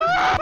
dog from anime's death sound Sound Button: Unblocked Meme Soundboard
Dog Sounds